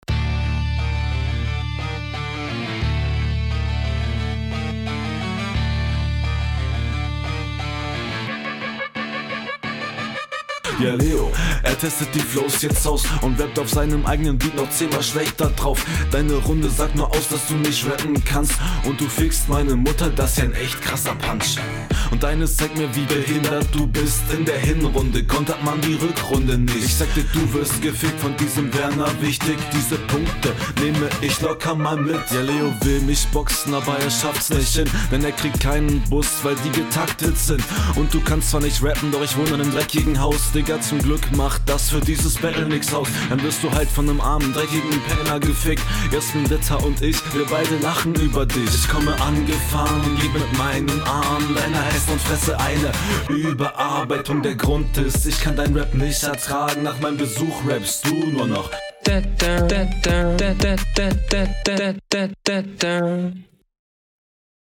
Manchmal komisch gerappt oder random pausen drin.